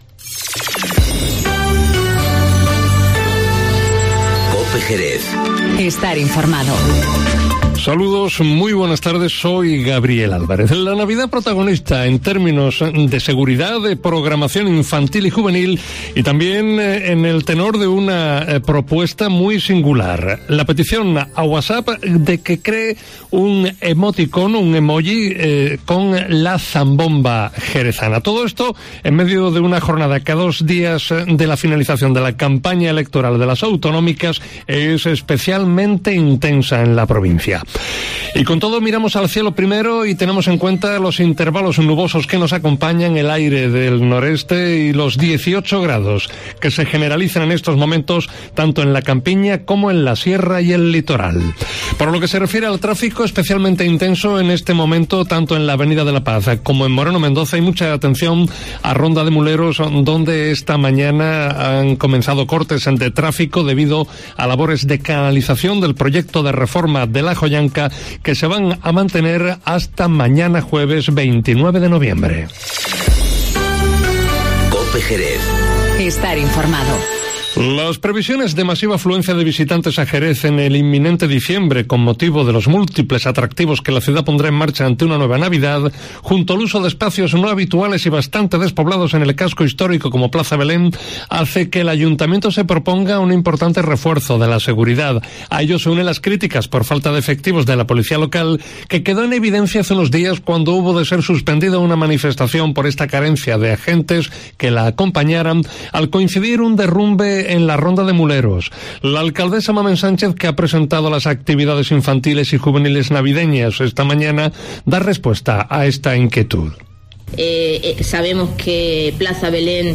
Informativo Mediodía COPE Jerez
La alcaldesa, Mamen Sánchez, que ha presentado las actividades infantiles y juveniles navideñas, da respuesta a esta inquietud.